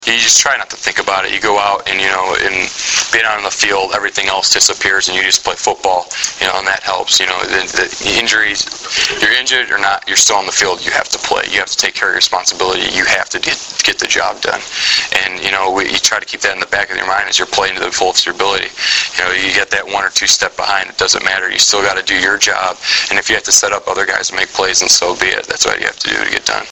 The following are audio links to press conference interview segments with Husker players and NU head coach Bill Callahan.